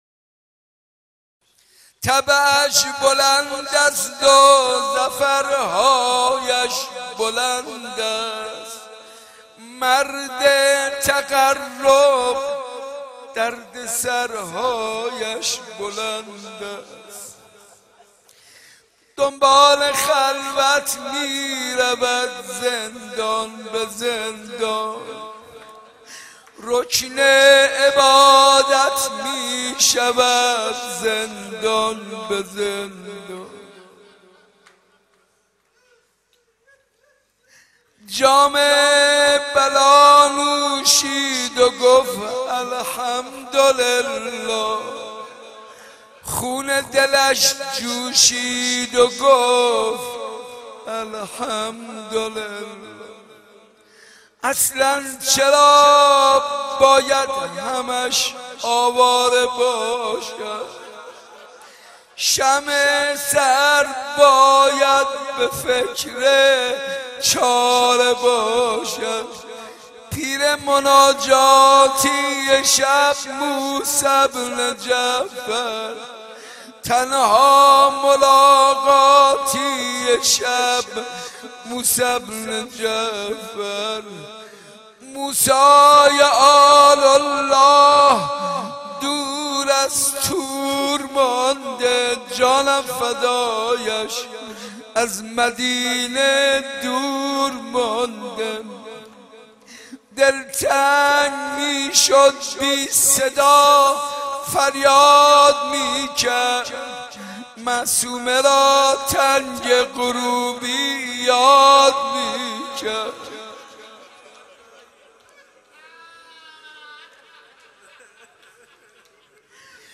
حاج منصور ارضی در جلسه هفتگی زیارت عاشورا
مراسم هفتگی زیارت عاشورا در حسینیه صنف لباس فروشان
به گزارش عقیق حاج منصور ارضی مداحی خود را با این شعر آغاز کرد